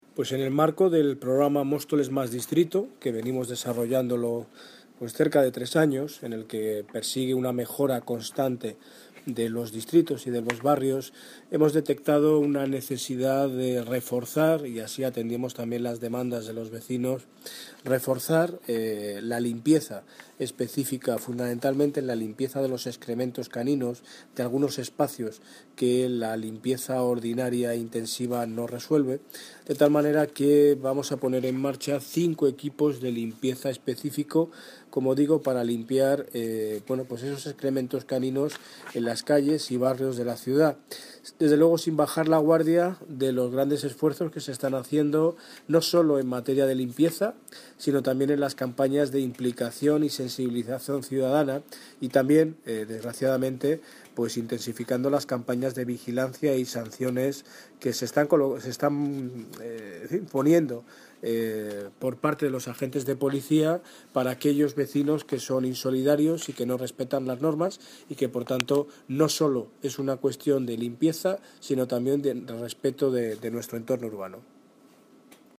Audio - Daniel Ortiz (Alcalde de Móstoles) Limpieza Excrementos